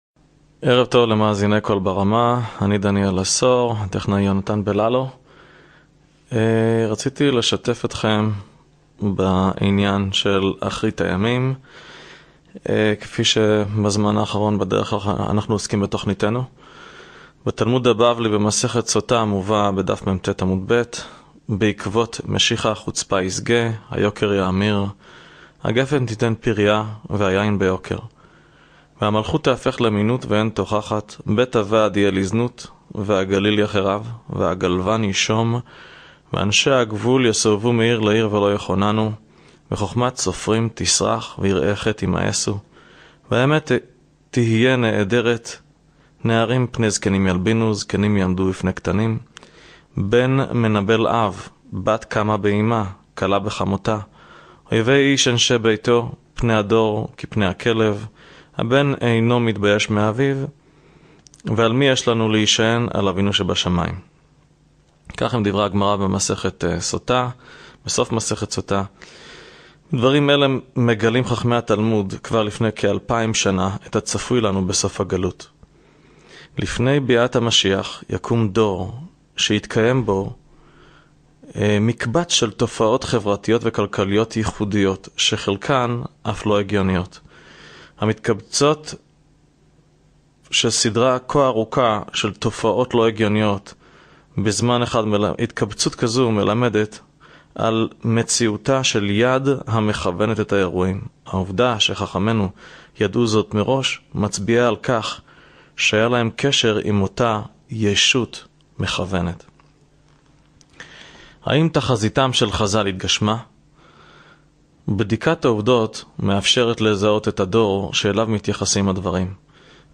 מרצה בקול ברמה . נושא : אחרית הימים .